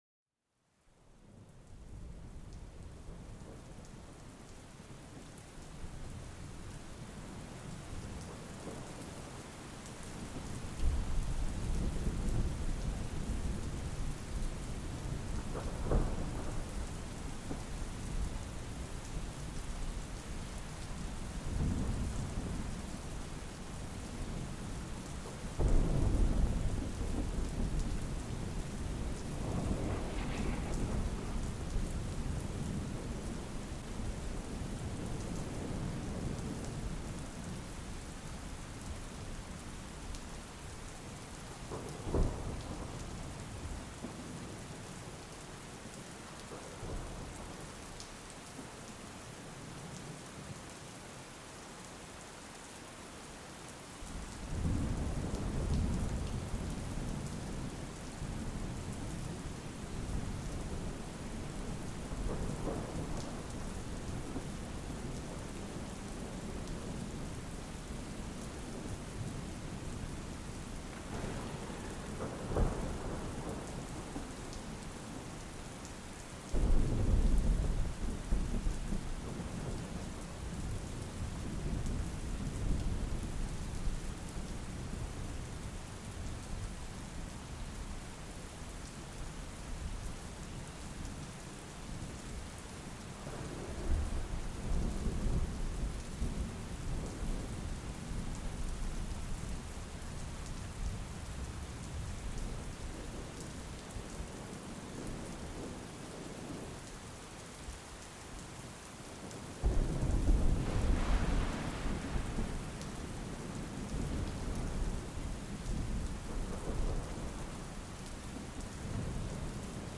⛈ Tormenta Relajante : Relájate con el sonido de una tormenta que calma la mente y reduce el estrés